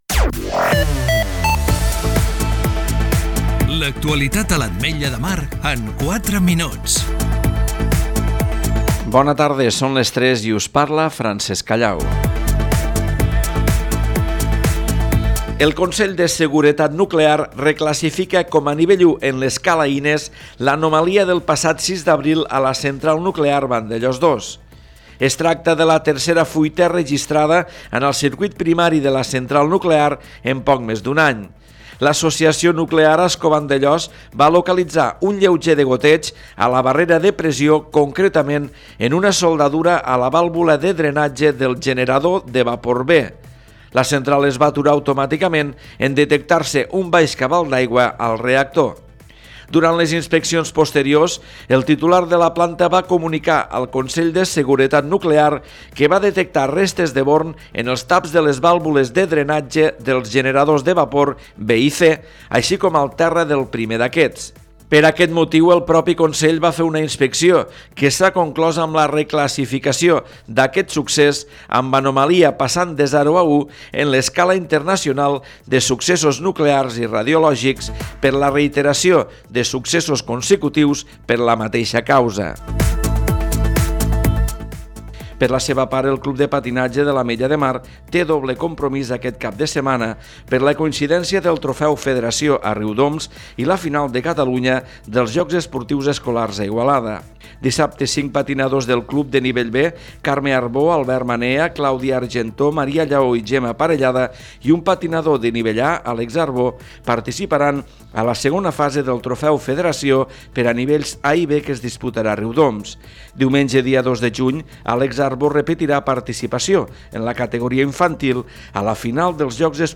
Butlletí 15 h